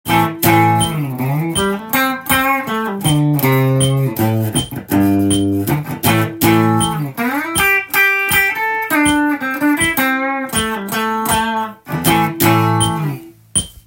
Amだけでギターソロ
２小節毎にAmのコードを８分音符で弾いていきます。
休符が付いているところは、すべてフリーでギターソロを弾きます。
Amなので、一番使用されるAmペンタトニックスケールが最初はおすすめです♪